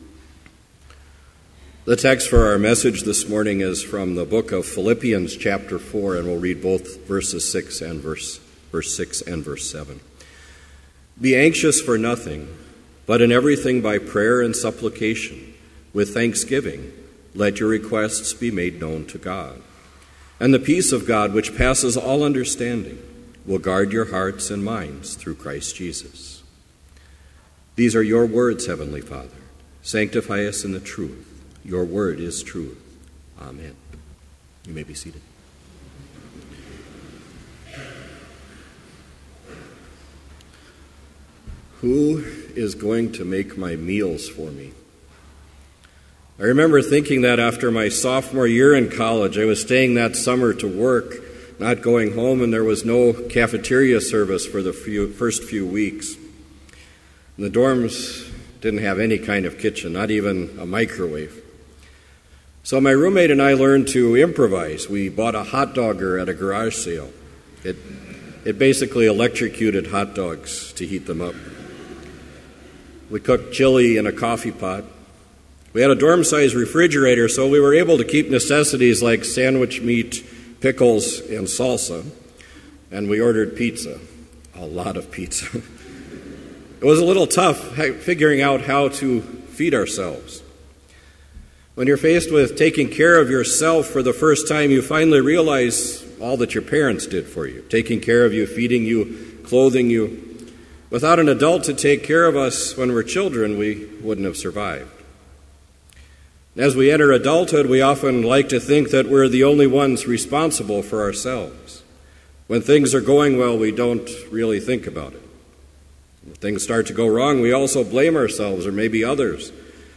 Complete Service
This Chapel Service was held in Trinity Chapel at Bethany Lutheran College on Thursday, May 14, 2015, at 10 a.m. Page and hymn numbers are from the Evangelical Lutheran Hymnary.